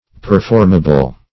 Performable \Per*form"a*ble\, a.